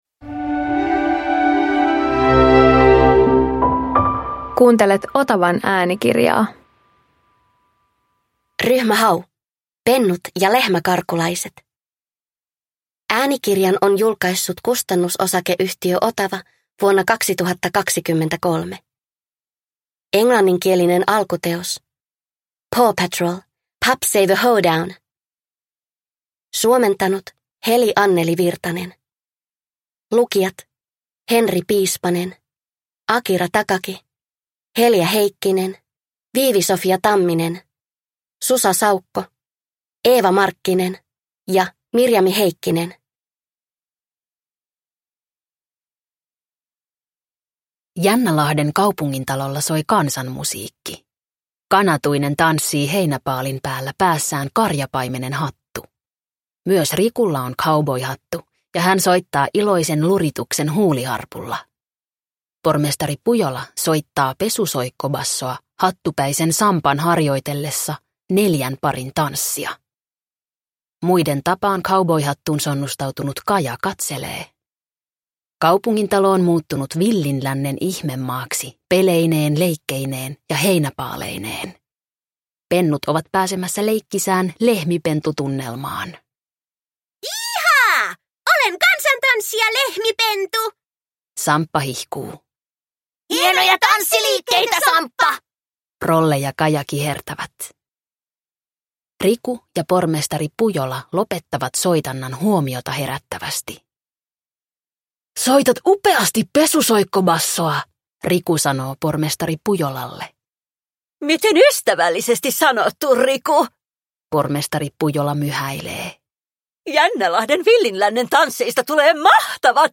Ryhmä Hau - Pennut ja lehmäkarkulaiset – Ljudbok